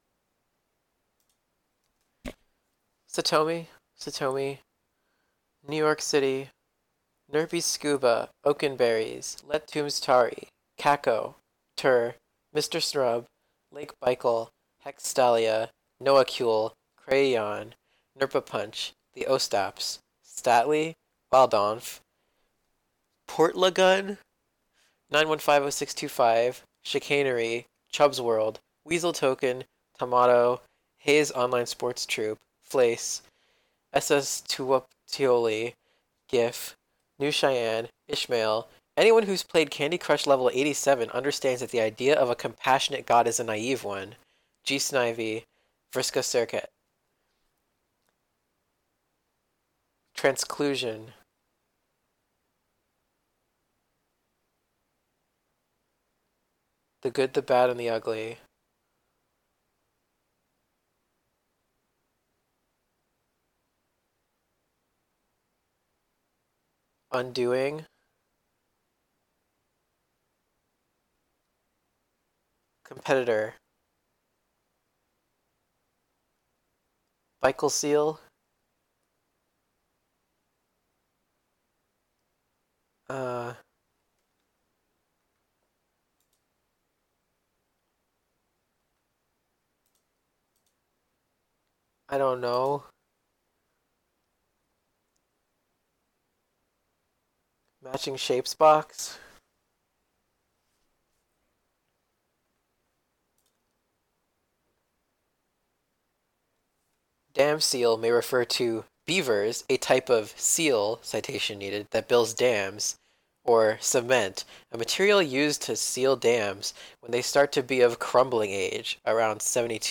Recordings of ourselves answering those questions.
ˈnɝpij ˈskuwbə ˈʔəwkɪnˌbe̞ɚɪjs lɛt̚ tʰuwms ˈtʰäɻij ˈkʰækəw tʰɝ ˈmɪstɚ sn̥ɚəb̚ lejk ˈbɐjkɫ̩ ˈhɛkstɐlˌɪjə ˈnowəˌkj̥uwl ˈkɻ̥ʷejän ˈnɝpʰə pʰənt͡ʃ ðij ˈəwstæps ˈstæt̚lij ˈwældɑ̃ːnf ˈpʰoɚt̚ləgən ˈnɐjnwənˌfɐjvəwˌsɪkstʰuwˈfɐjf